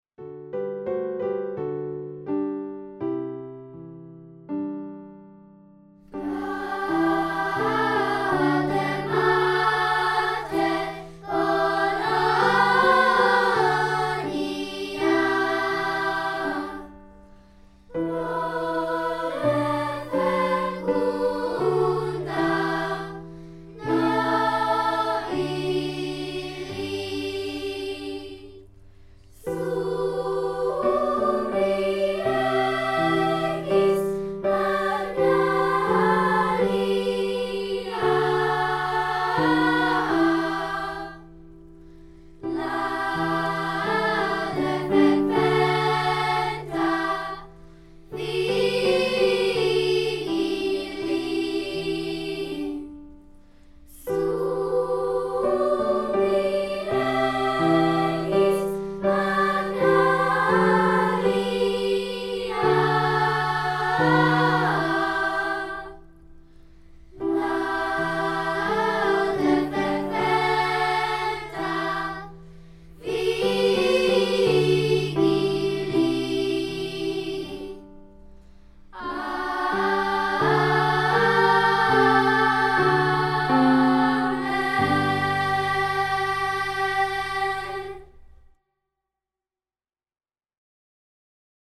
Gaude Mater - tylko chor